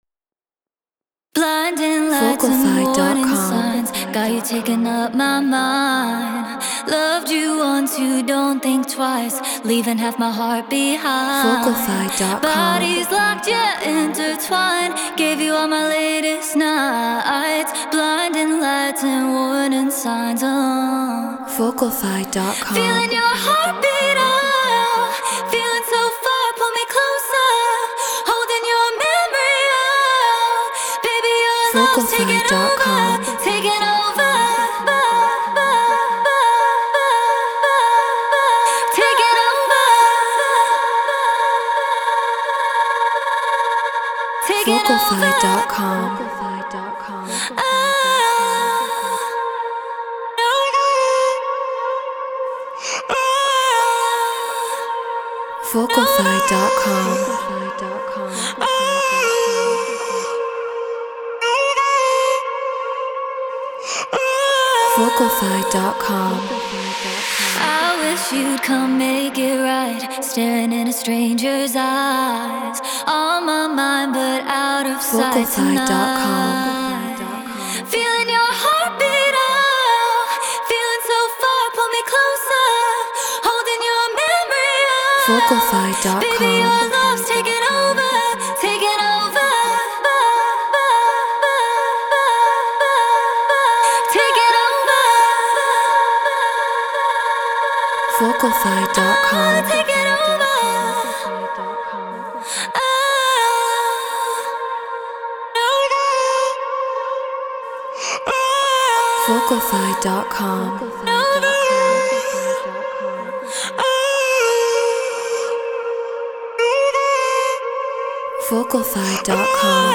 Drum & Bass 174 BPM A#min